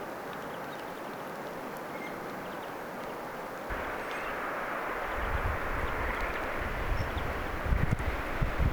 onko_tuo_tuuliviiri_tai_jotain.mp3